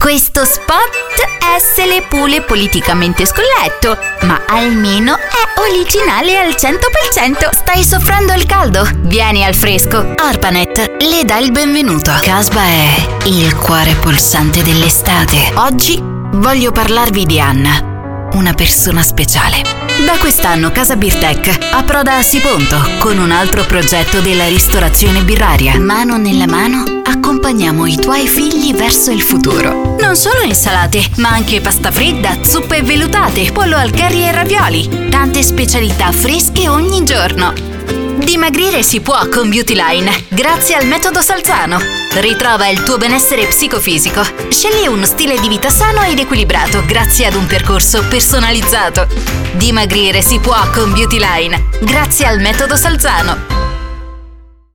I have my own recording studio, equipped with the most advanced devices on the market.
Sprechprobe: Werbung (Muttersprache):
I record and mix everyday full day into my real professional home studio.